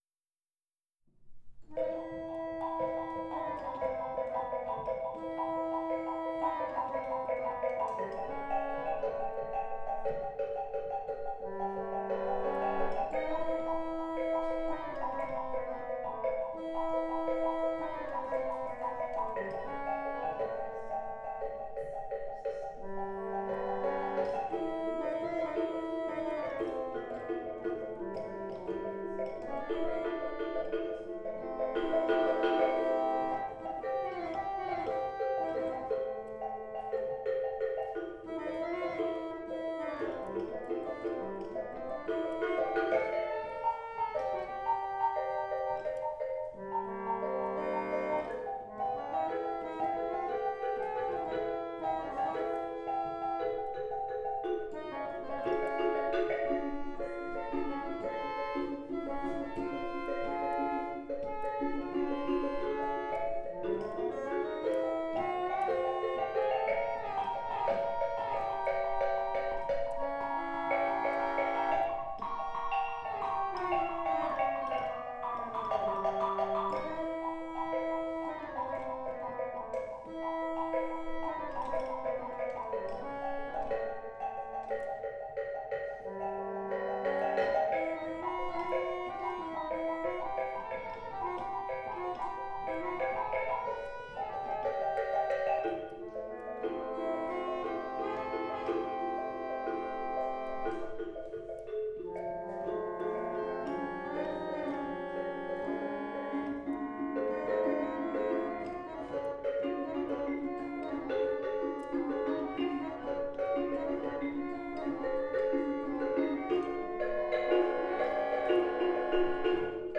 Partch Instrumentarium
The first, Facets, is an instrumental duet for Partch’s Chromelodeon I and Diamond Marimba.